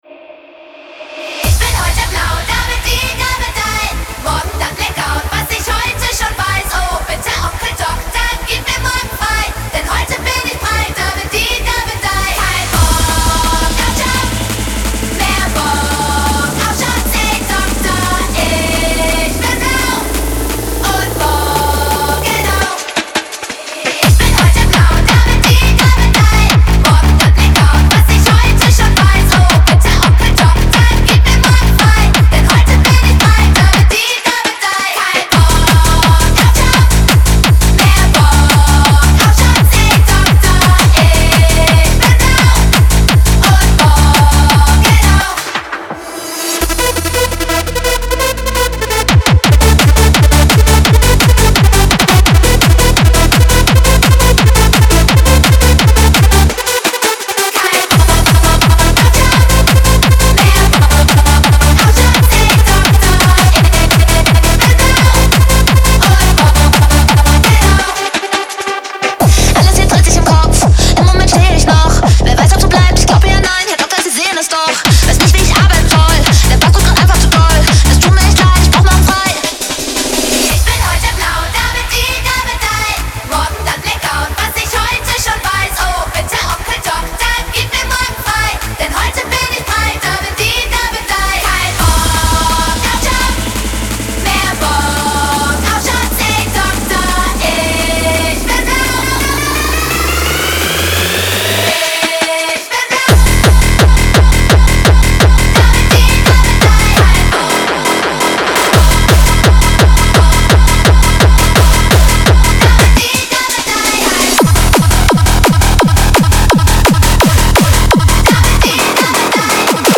• Жанр: Hardstyle, Dance